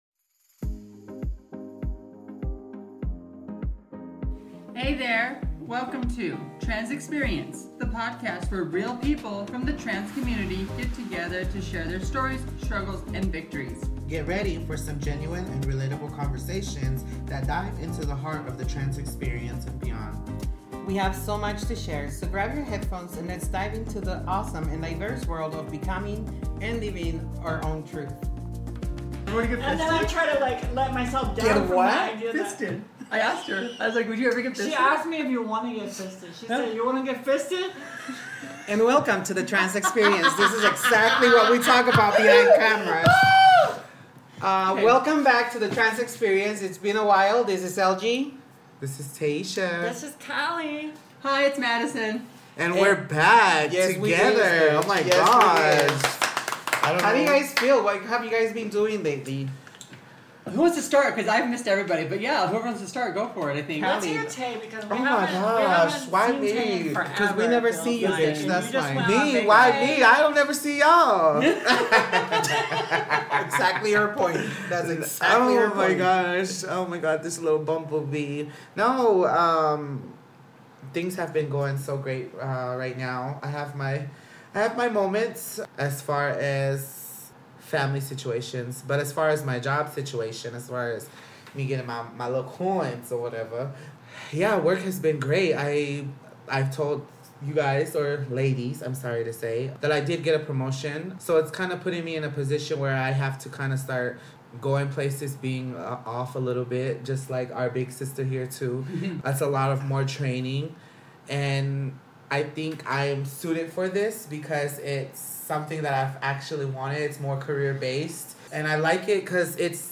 In a fun, loving, and heartfelt chat. The girls shared the challenges of their last two months.